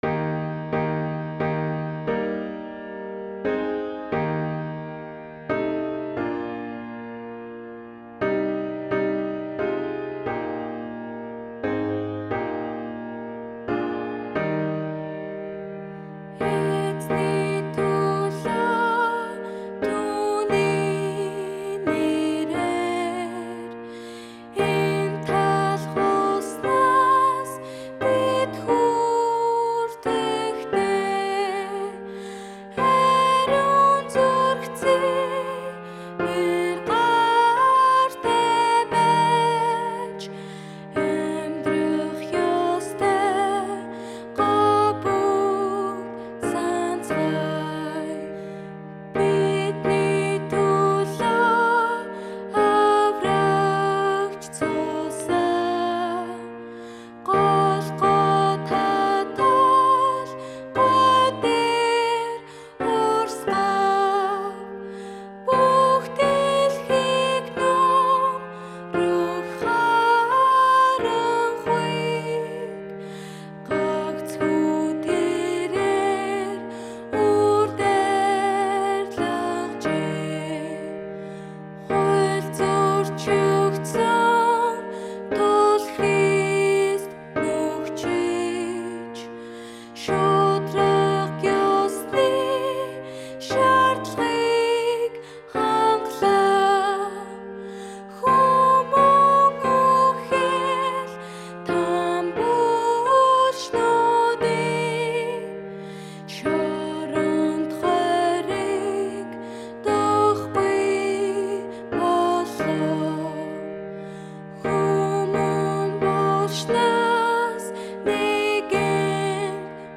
– Mongolian hymnal
Израилын Цагаатгагч (MP3 audio (vocal))